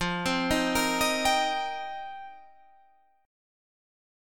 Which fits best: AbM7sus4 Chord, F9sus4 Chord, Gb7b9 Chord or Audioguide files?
F9sus4 Chord